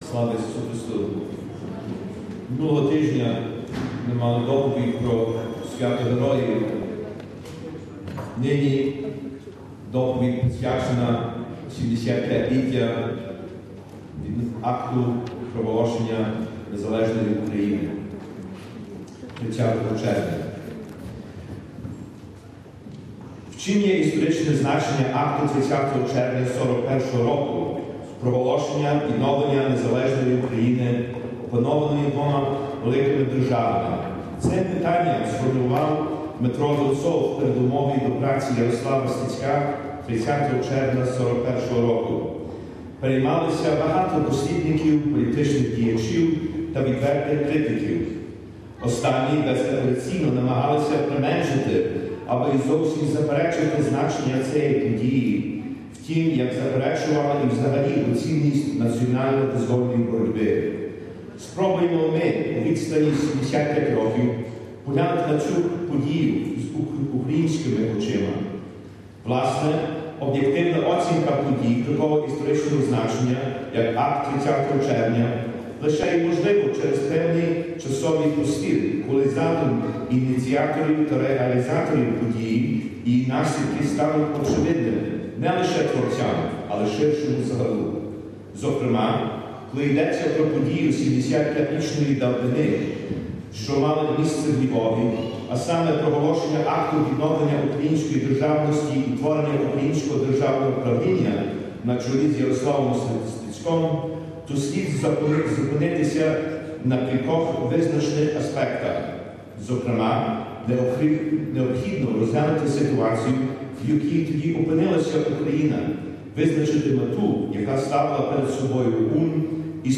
Report.